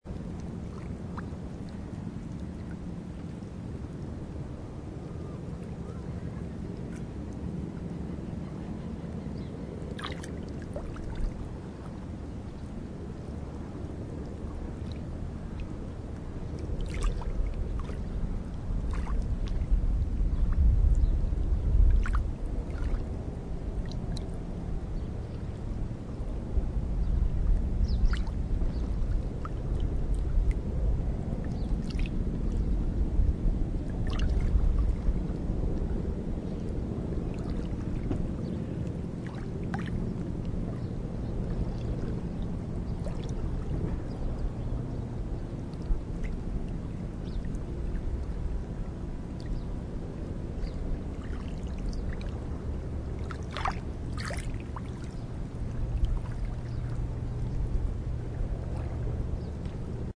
Hurghada Beach, Sea Waves, Birds
hurghada-beach.mp3